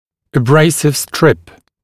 [ə’breɪsɪv strɪp][э’брэйсив стрип]абразивный штрипс, абразивная штрипса